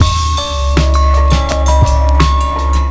Index of /90_sSampleCDs/Zero-G - Total Drum Bass/Drumloops - 3/track 51 (165bpm)